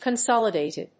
音節con・sól・i・dàt・ed 発音記号・読み方
/‐ṭɪd(米国英語), kʌˈnsɑ:lʌˌdeɪtʌd(英国英語)/